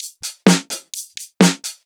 Index of /VEE/VEE Electro Loops 128 BPM
VEE Electro Loop 238.wav